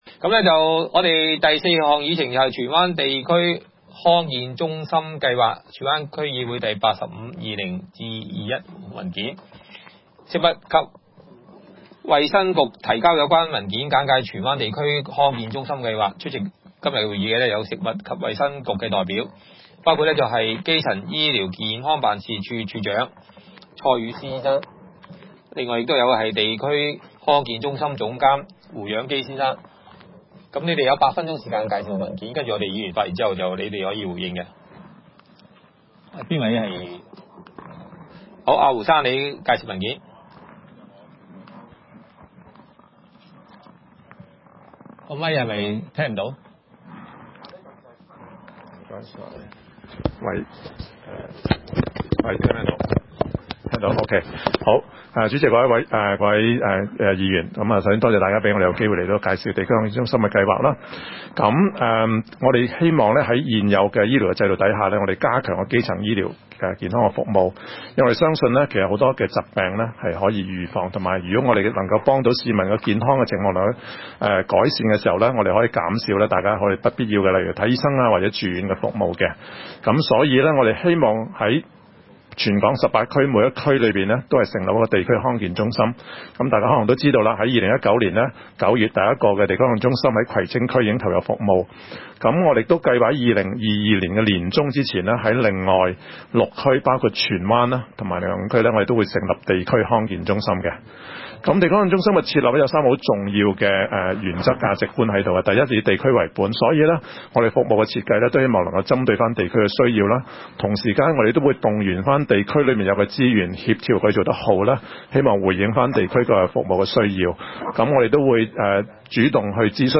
區議會大會的錄音記錄